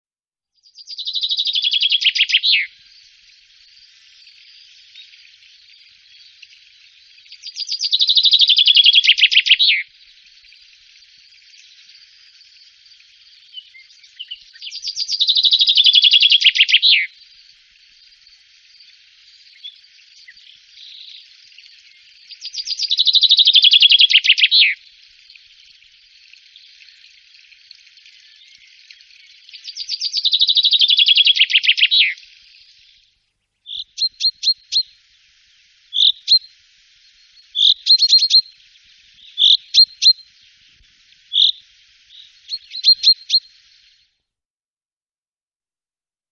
die genannten und abgebildeten Vögel sind im Park anzutreffen
Buchfink
Buchfink.MP3